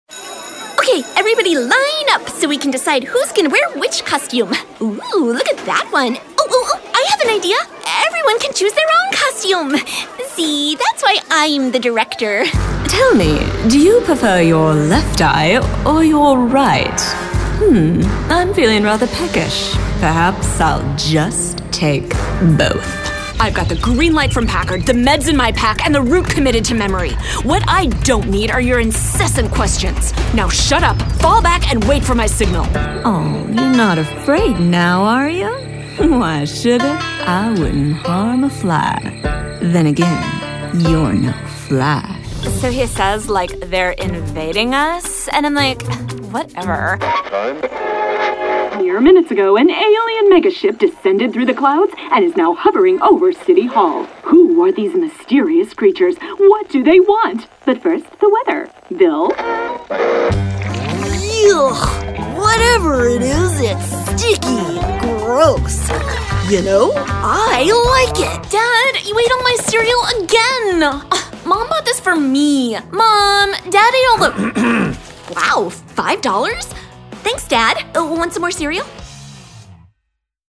Character Professional Voice Over Talent | VoicesNow Voiceover Actors
Male and Female Character voice over talent.